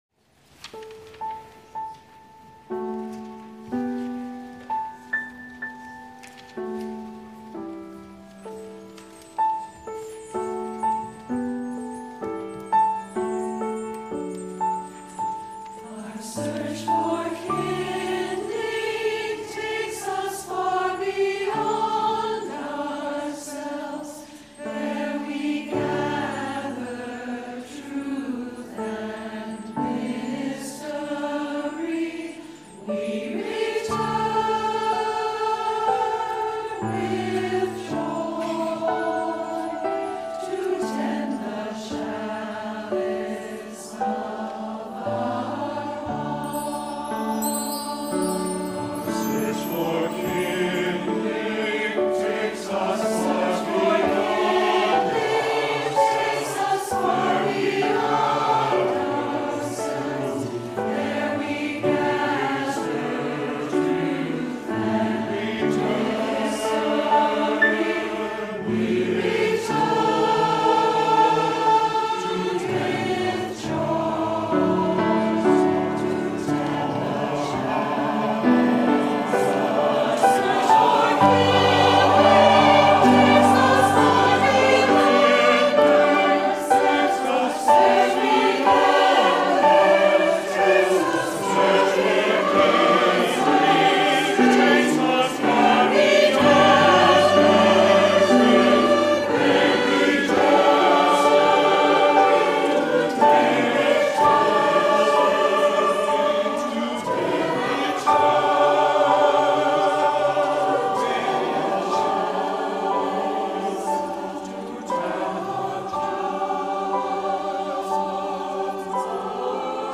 SATB, flute, horn, violin, cello, piano OR SATB, piano